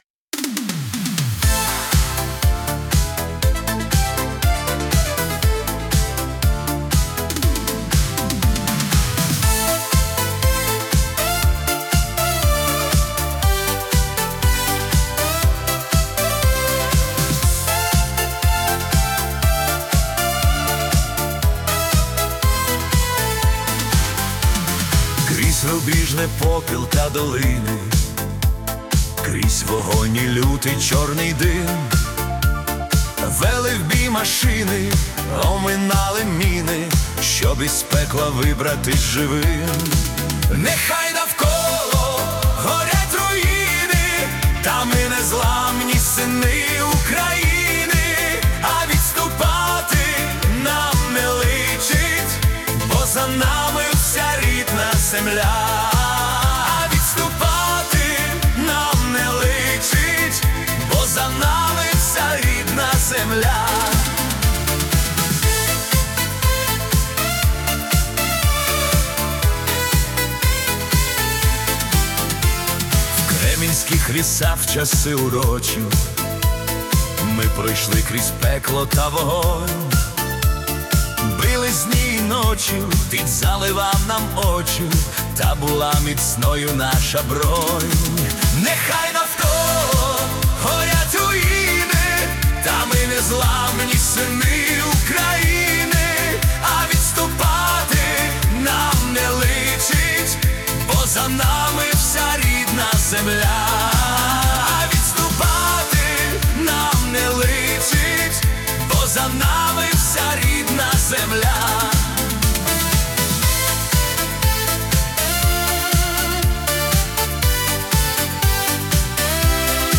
80s Upbeat Ukrainian Estrada Пісня